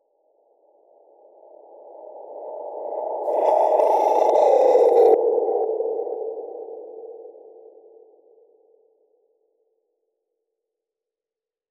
AV_Troll_Reverse_FX
AV_Troll_Reverse_FX.wav